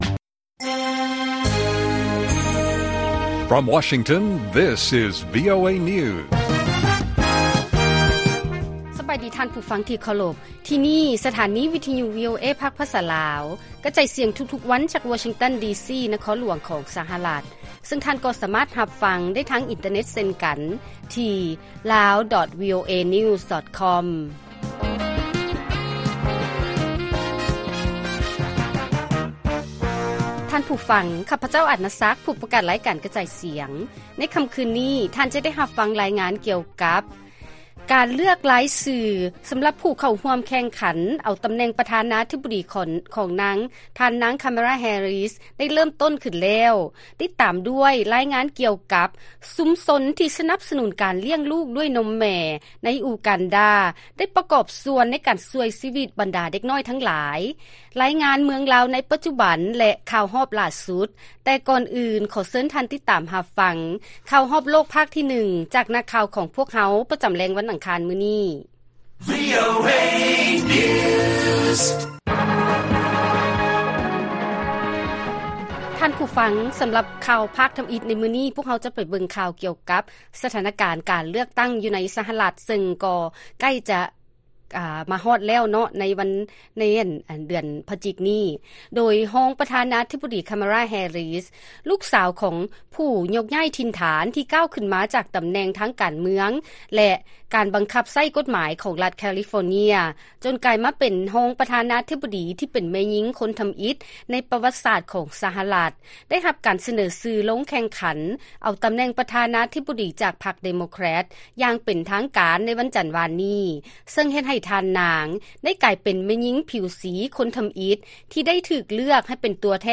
ລາຍການກະຈາຍສຽງຂອງວີໂອເອລາວ: ທ່ານນາງ ຄາມາລາ ແຮຣີສ ເປັນຜູ້ຖືກສະເໜີຊື່ຊິງຕຳ ແໜ່ງປະທານາທິບໍດີ ຈາກພັກເດໂມແຄຣັດ ແລະຈະ ປະເຊີນໜ້າກັບ ທ່ານ ດໍໂນລ ທຣຳ